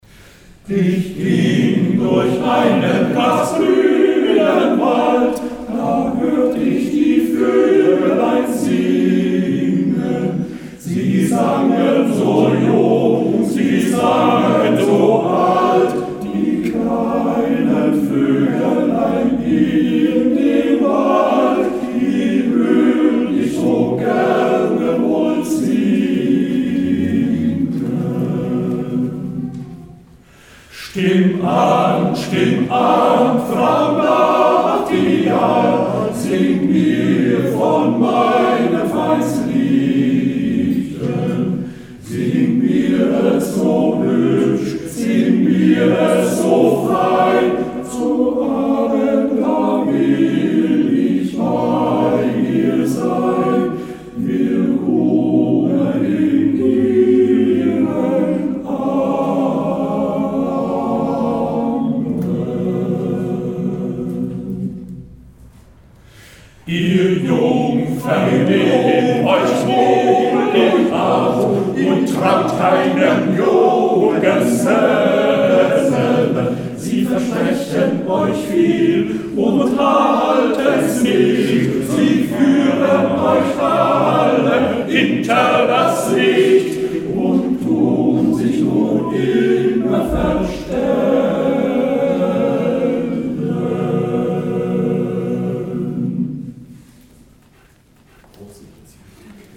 Hörbeispiele von unserer CD vom 27. Juni 2007: 70. Hausmusik.